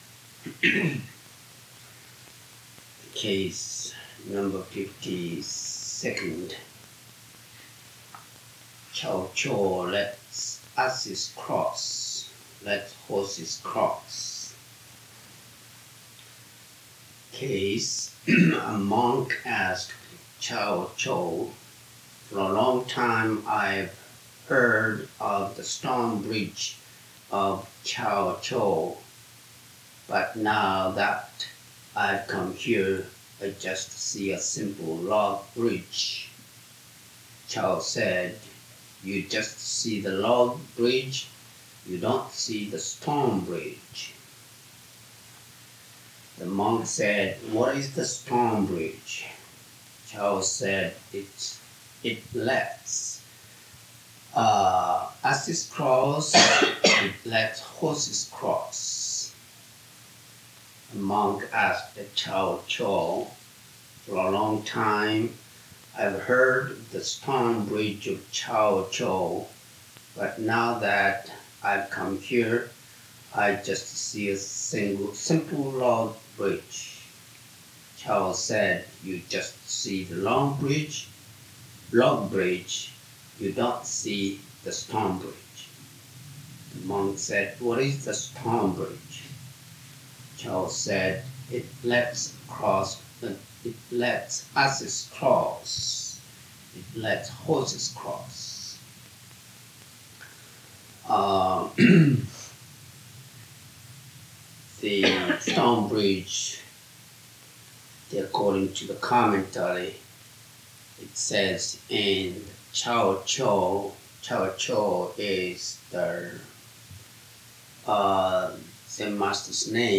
January 21, 1984 Dharma Talk by Dainin Katagiri Roshi